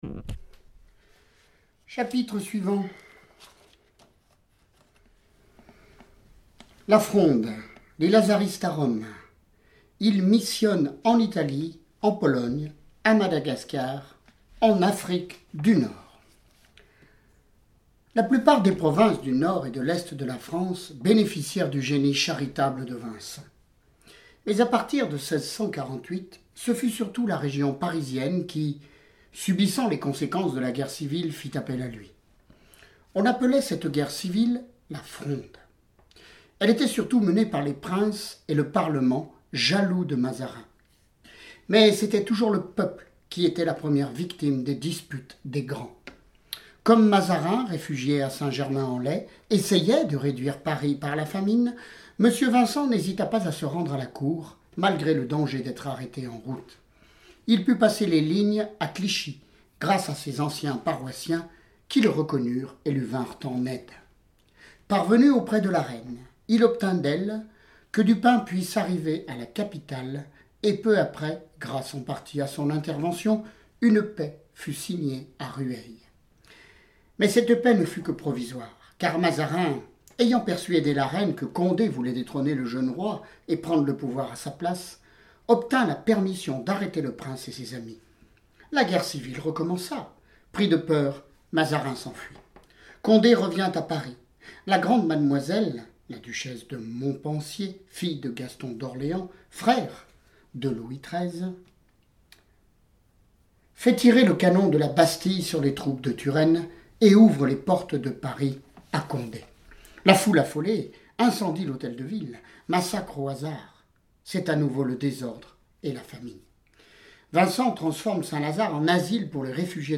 Lecture de vie de Saints et Saintes >> Saint Vincent de Paul